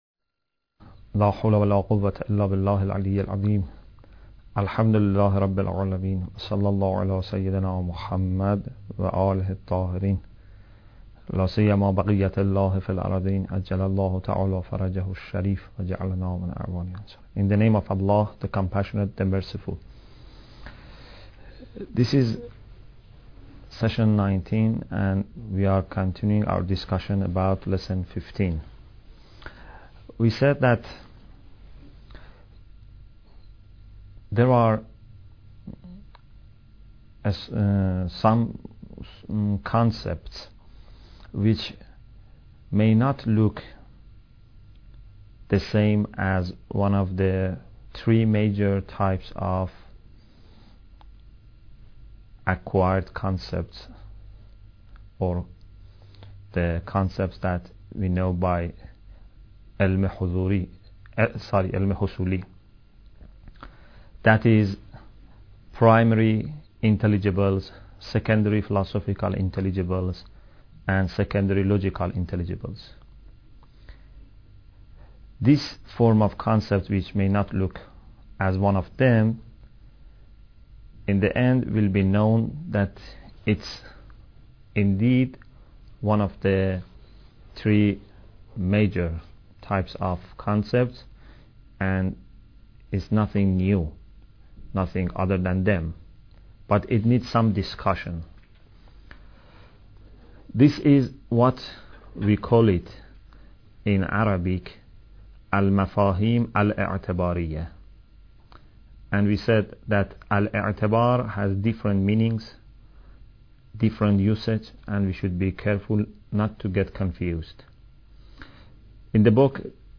Bidayat Al Hikmah Lecture 19